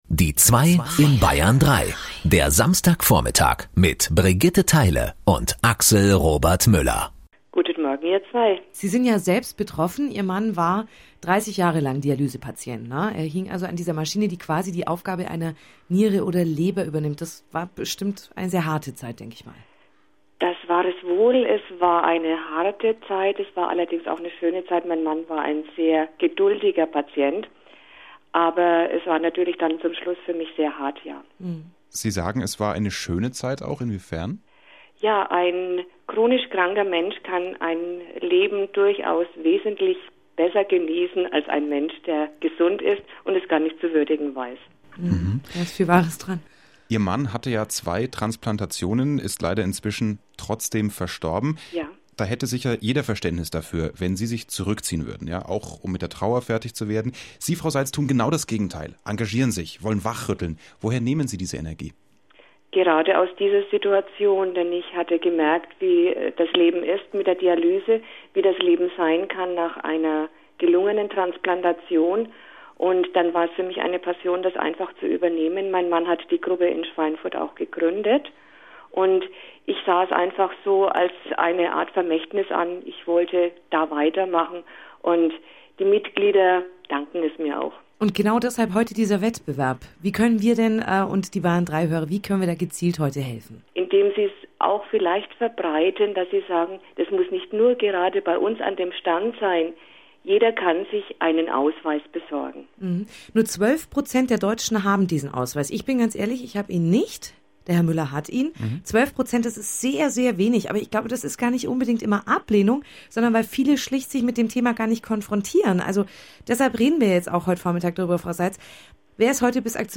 Radio-Beitrag zum Organspende-Wettbewerb - Bayern 3